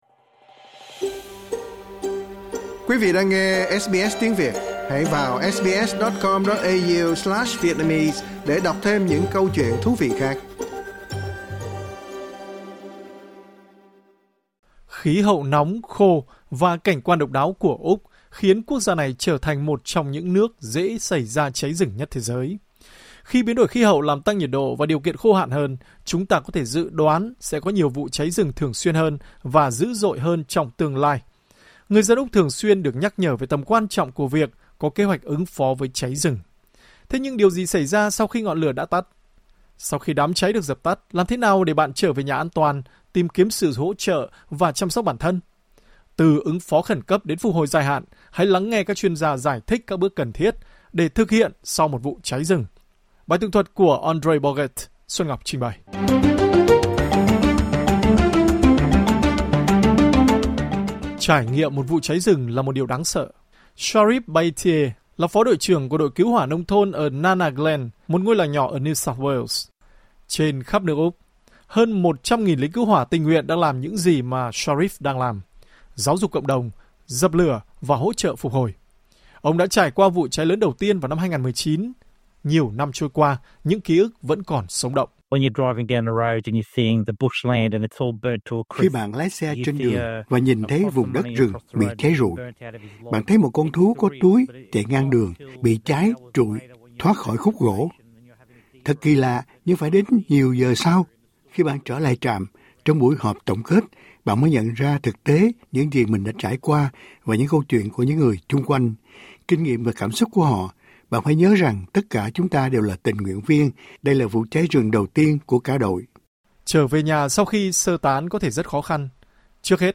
Sau khi đám cháy được dập tắt, làm thế nào để bạn trở về nhà an toàn, tìm kiếm sự hỗ trợ và chăm sóc bản thân? Từ ứng phó khẩn cấp đến phục hồi dài hạn, hãy lắng nghe các chuyên gia giải thích các bước cần thiết cần thực hiện sau một vụ cháy rừng.